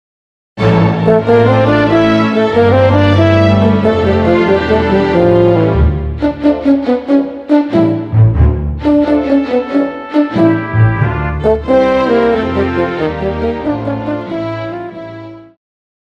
Classical
French Horn
Band
Instrumental
Only backing